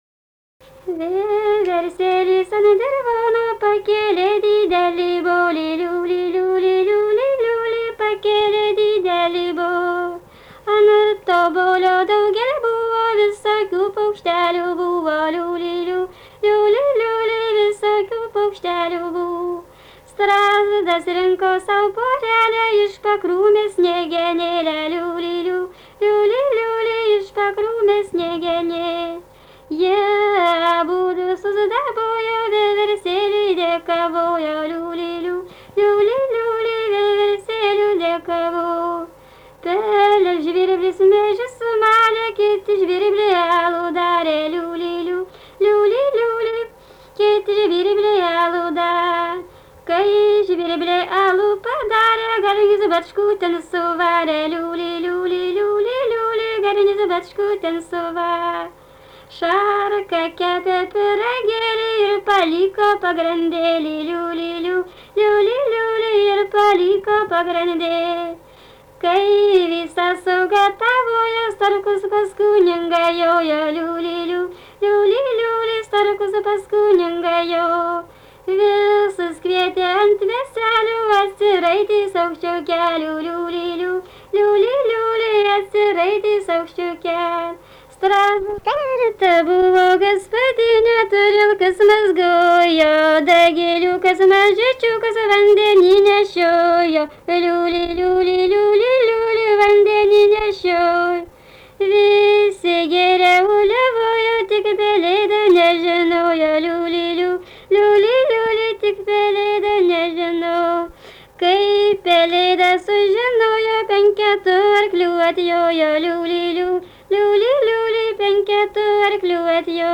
daina, karinė-istorinė
Atlikimo pubūdis vokalinis